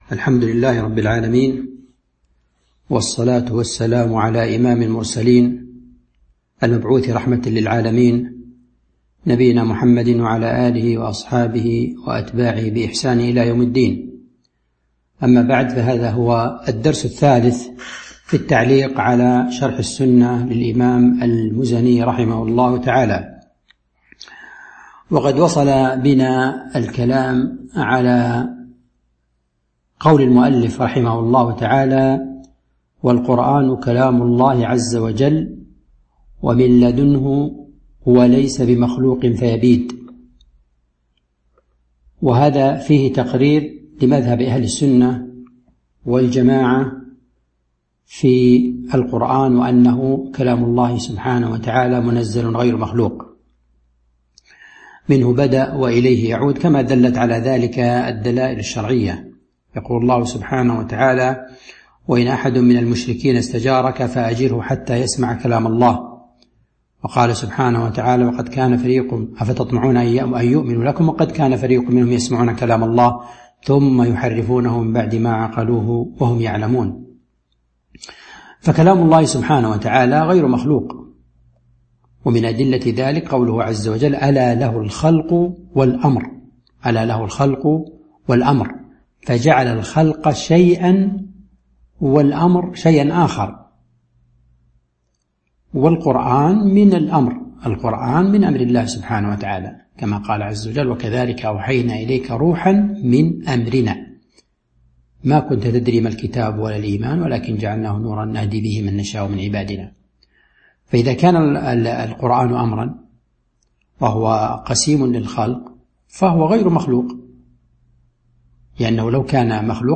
تاريخ النشر ٢٨ ذو الحجة ١٤٤٢ هـ المكان: المسجد النبوي الشيخ